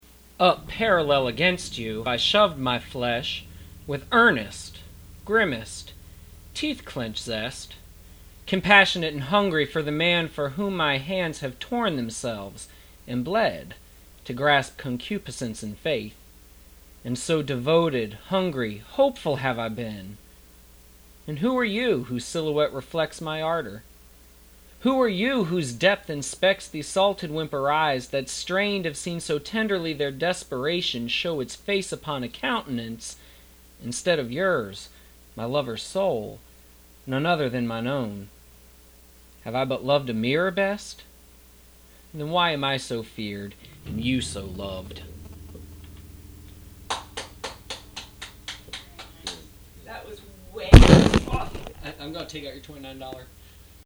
recite his poetry